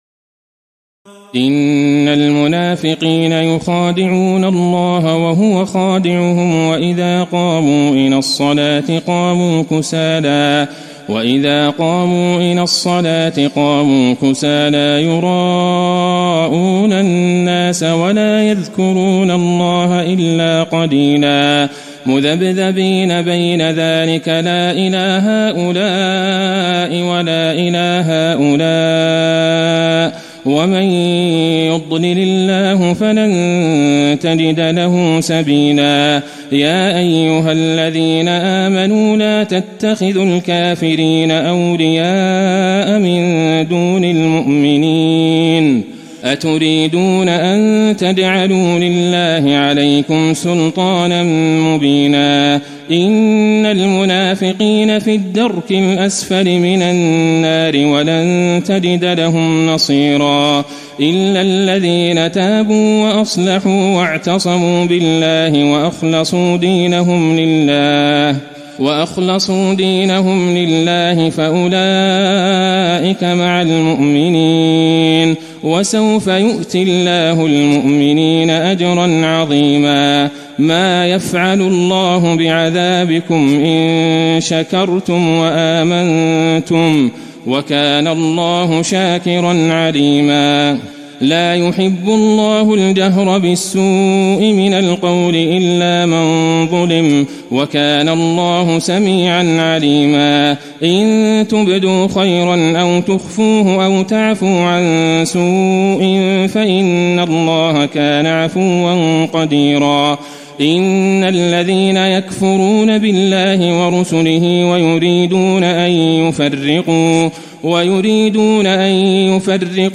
تراويح الليلة الخامسة رمضان 1435هـ من سورتي النساء (142-176) و المائدة (1-26) Taraweeh 5 st night Ramadan 1435H from Surah An-Nisaa and AlMa'idah > تراويح الحرم النبوي عام 1435 🕌 > التراويح - تلاوات الحرمين